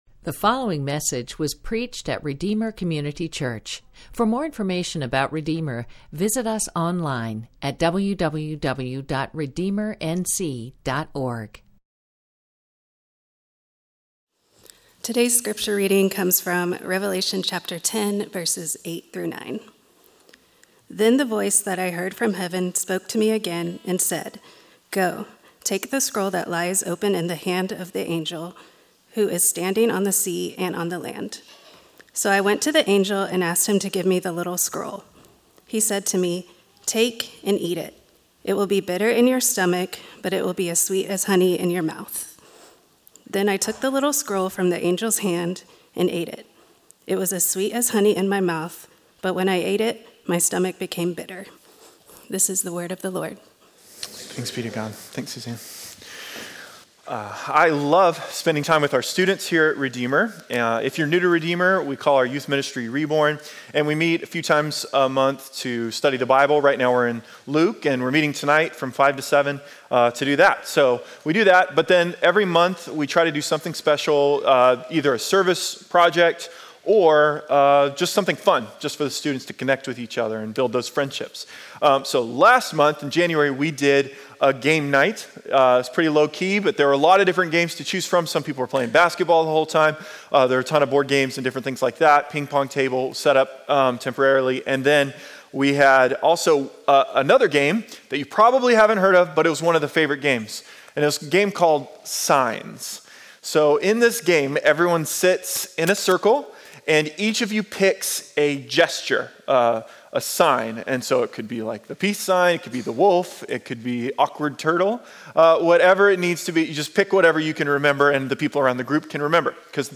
A sermon from the series "Stand-Alone Sermons."